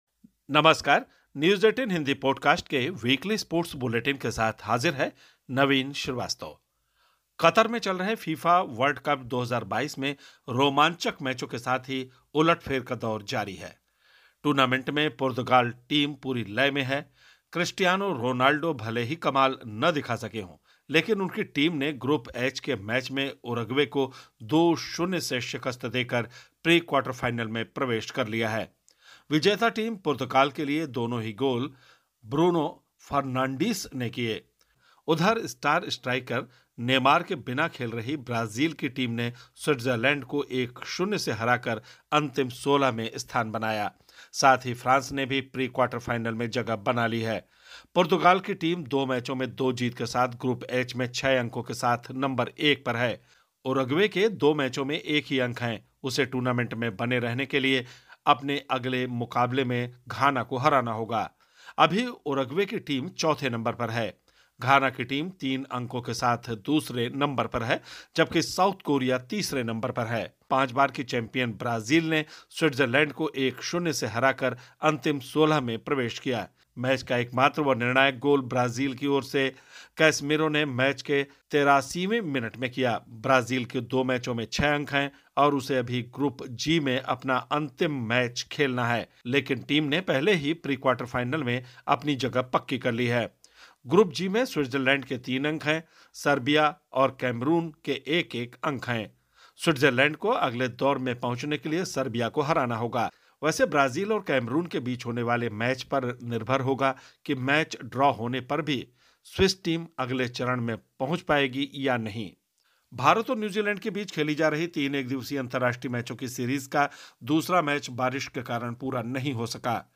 स्पोर्ट्स बुलेटिन / Podcast: 1 ओवर में 7 छक्के जड़ ऋतुराज ने तोड़ा सर गैरी सोबर्स जैसे सात दिग्‍गज क्रिकेटरों का रिकार्ड!